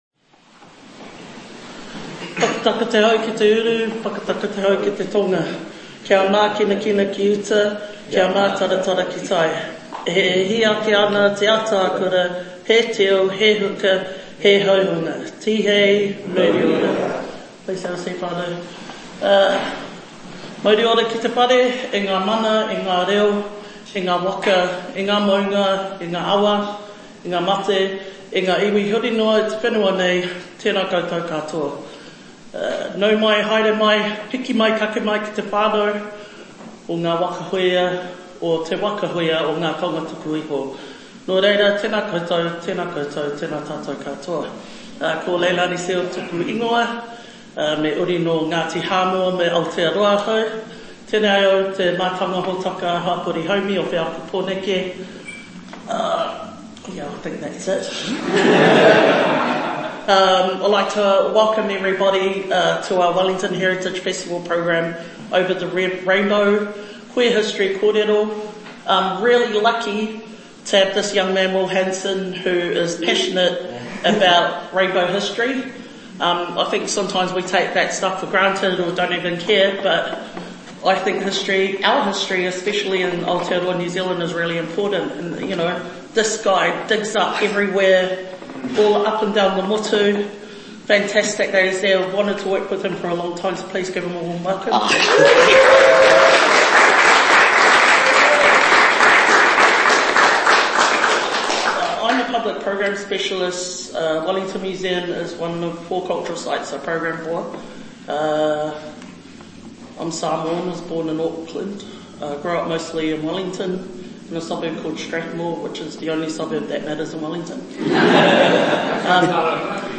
Audience questions
Over the Rainbow – Queer History Kōrero is a vibrant, intergenerational conversation recorded at Wellington Museum as part of the 2025 Wellington Heritage Festival.